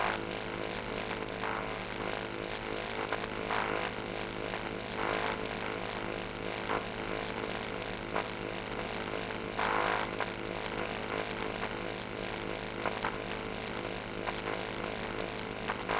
pgs/Assets/Audio/Sci-Fi Sounds/Hum and Ambience/Hum Loop 7.wav at 7452e70b8c5ad2f7daae623e1a952eb18c9caab4
Hum Loop 7.wav